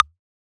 edm-perc-35.wav